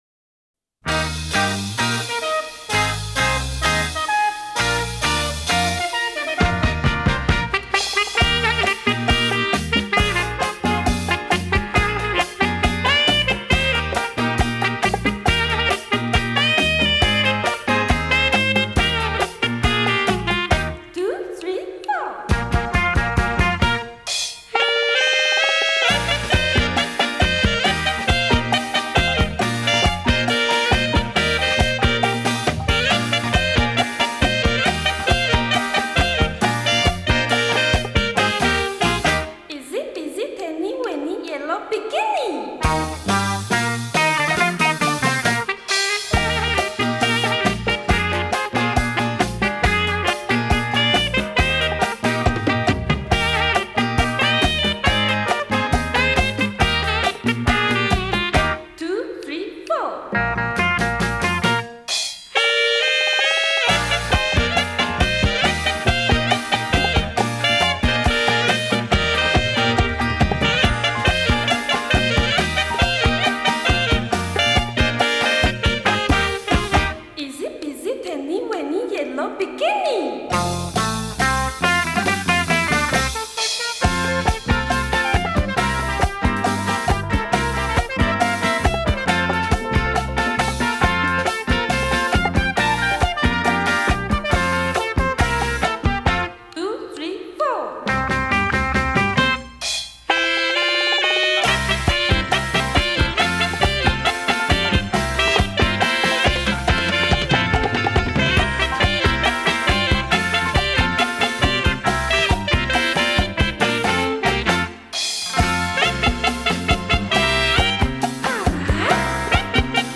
属拉丁风格。